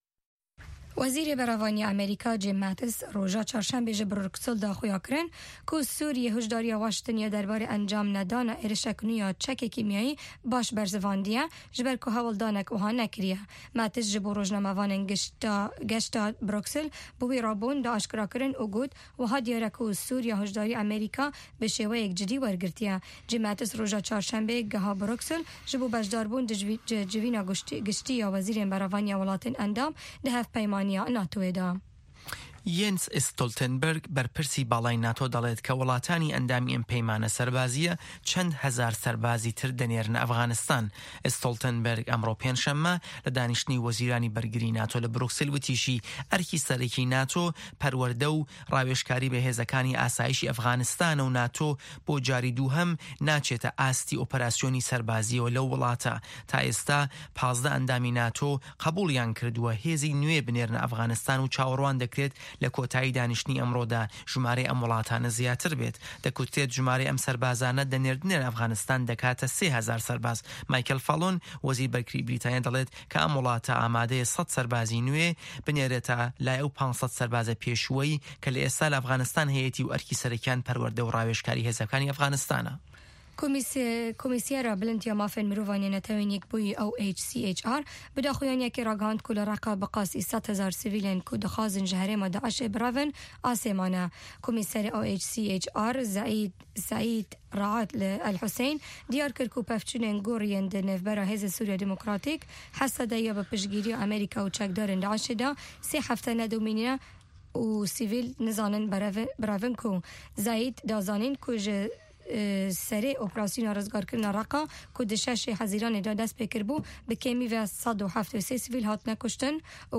هه‌واڵه‌کان، ڕاپـۆرت، وتووێژ و پاشان سه‌رگوتاری ڕۆژنامه‌ که‌ تیایدا ڕاوبۆچوونی حکومه‌تی ئه‌مه‌ریکا ده‌خرێته‌ ڕوو.